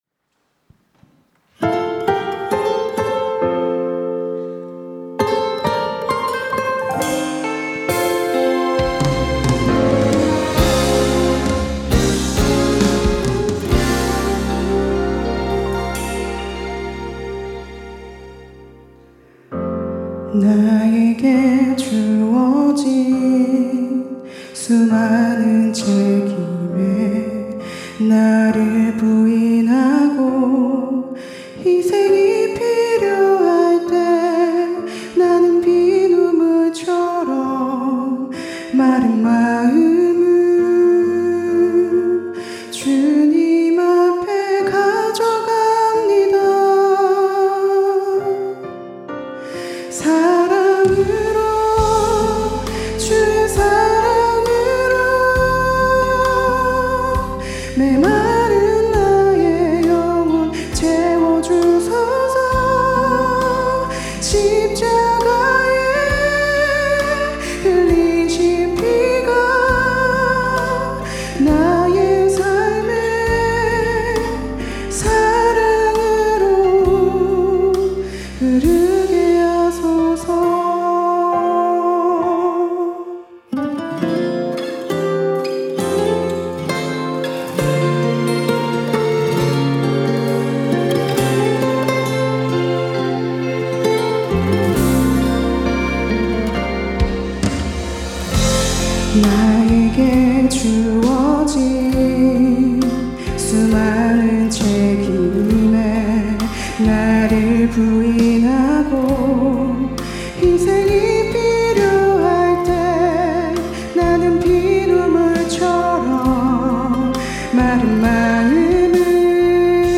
특송과 특주 - 사랑으로